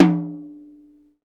TOM XTOMHI0J.wav